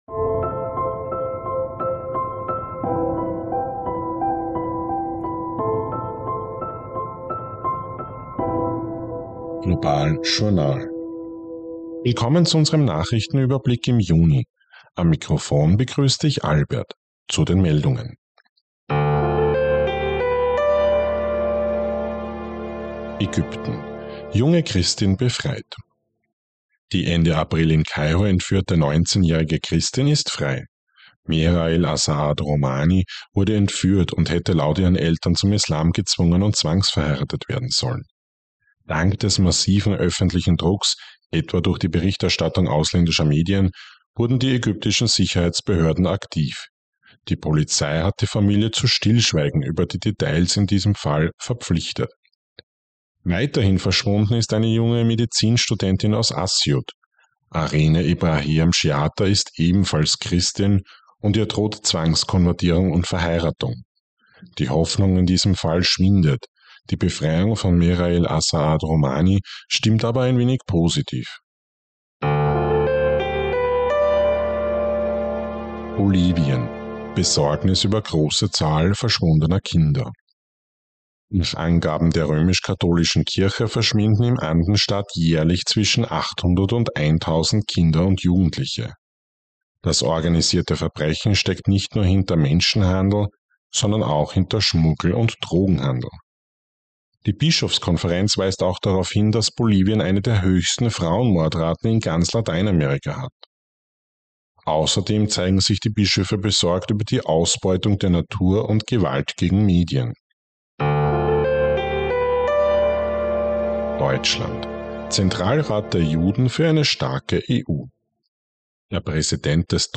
News Update Juni 2024